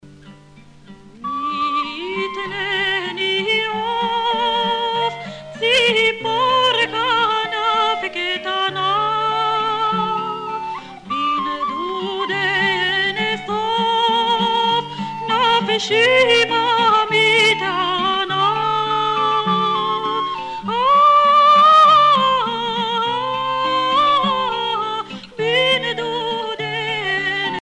Chants et danses des pionniers